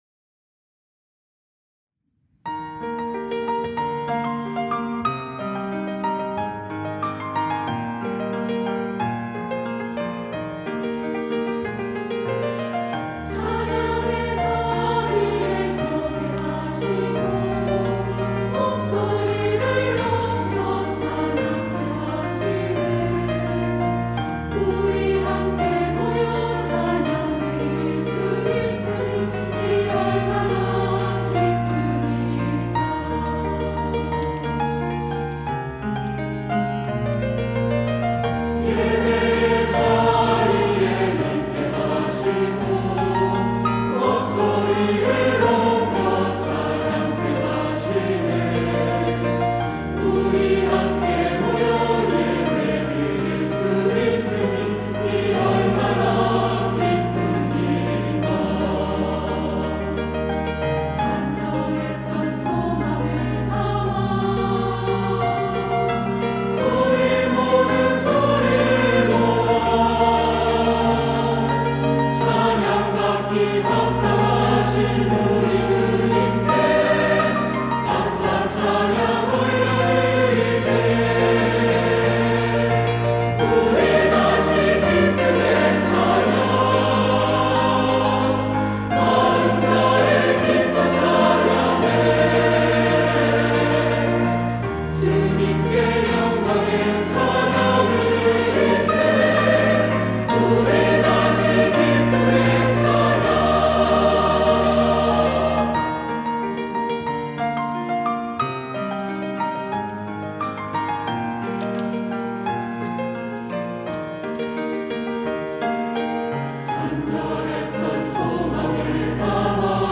할렐루야(주일2부) - 우리 다시 기쁨의 찬양
찬양대